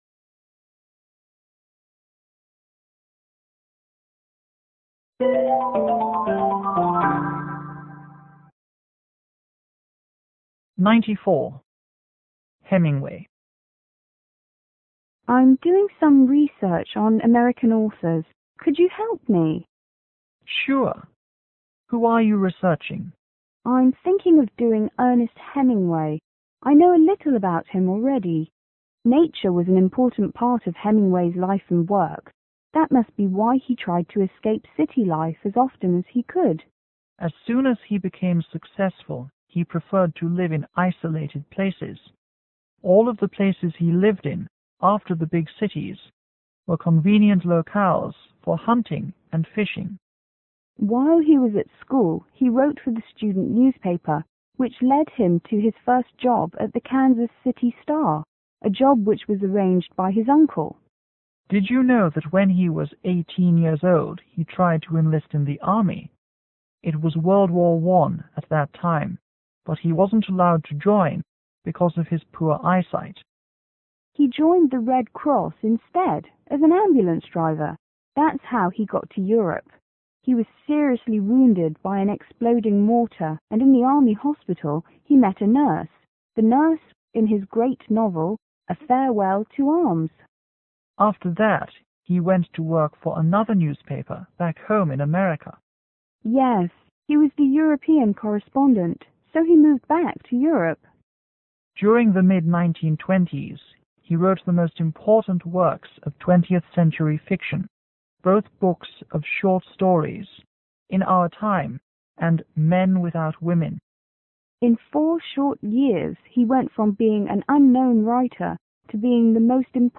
S1 : International student       S2 :  Local student